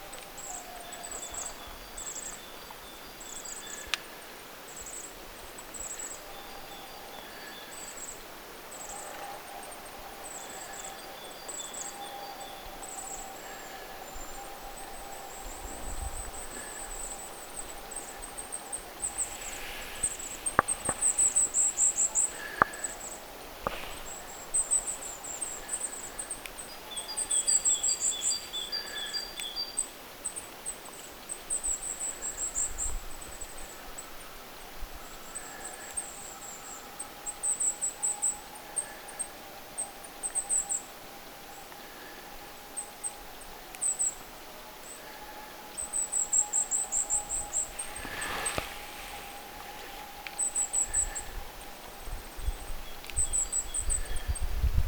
hippiäisten jotain keväistä ääntelyä
Äänite: koiras "ajaa naarastaan takaa",
se on innoissaan
hippiaisten_jotain_kevaista_aantelya.mp3